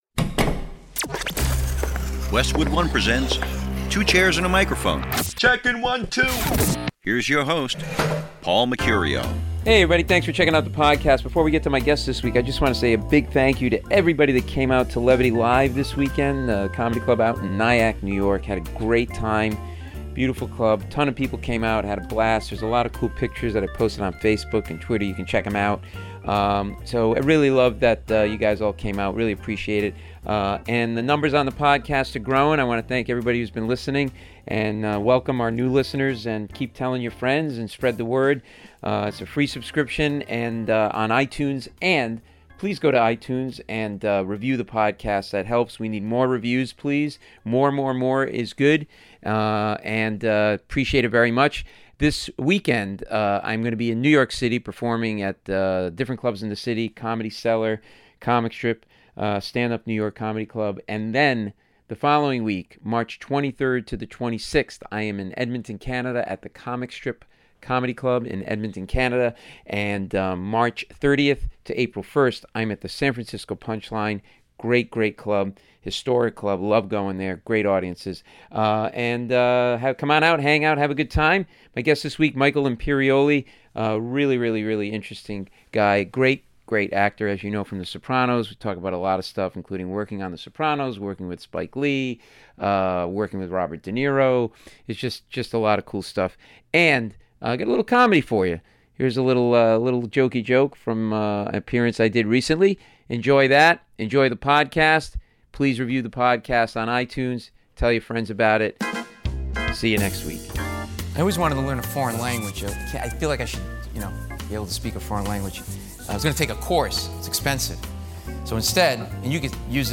One of the most interesting interviews I've done, Michael has great stories about acting in "Goodfellas" opposite legends, Robert DeNiro and Joe Pesci and how he prepared for the big scene where Pesci kills his character - fascinating look at how a great actor like Michael wor...